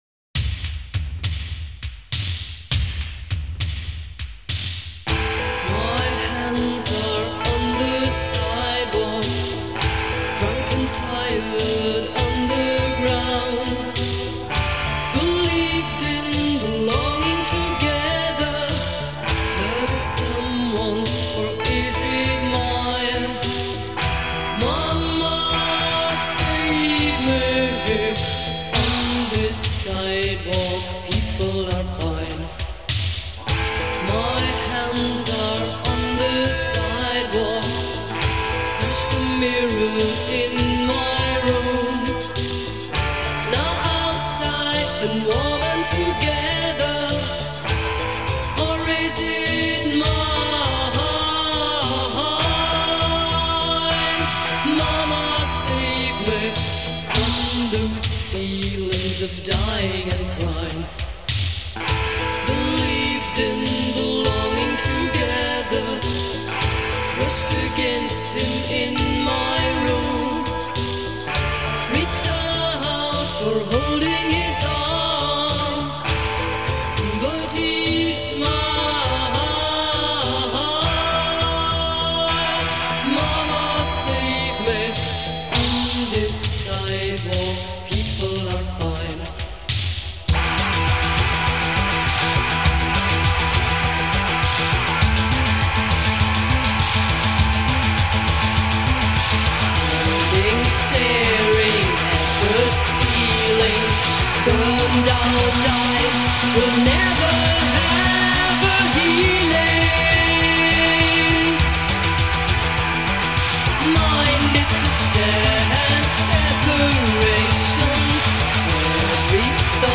Electric Guitar
Vocals